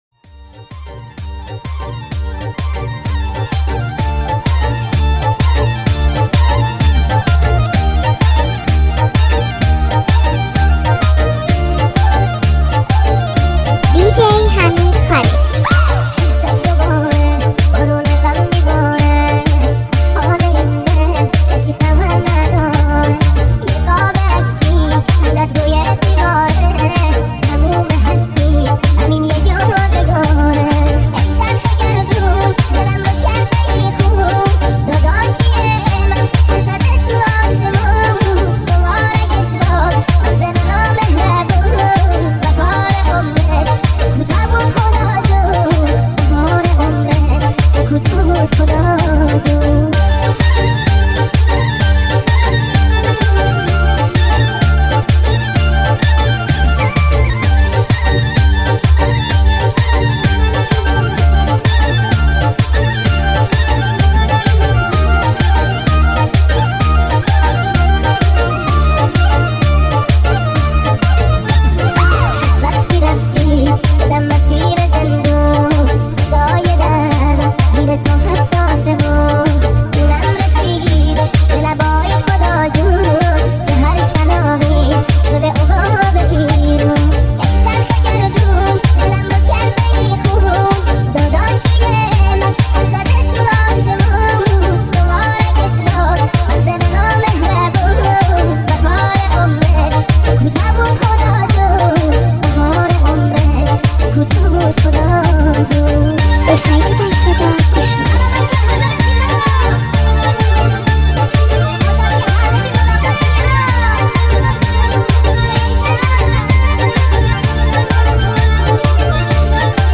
ریمیکس شده با صدای بچه نازک شده